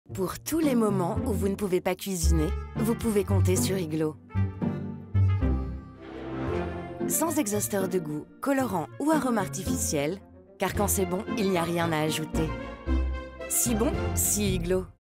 Promotions
Artiste inspirée, attentive, disponible et polyvalente, avec une voix grave-médium.
Cabine DEMVOX, micro Neumann TLM 103, Scarlett 4i4 et ProTools Studio sur un Mac M1